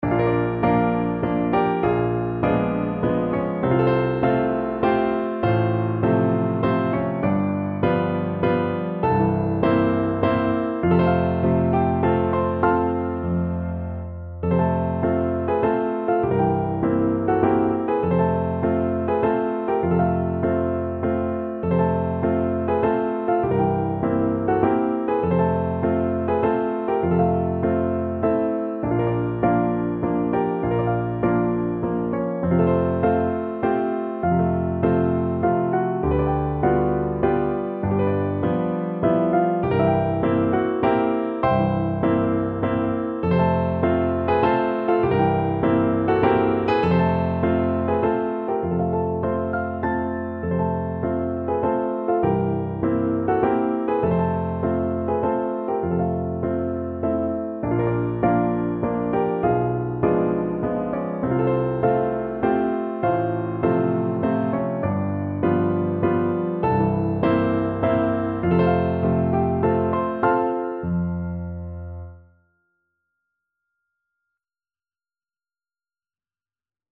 ~ = 100 Slowly and dreamily
3/4 (View more 3/4 Music)
Classical (View more Classical Voice Music)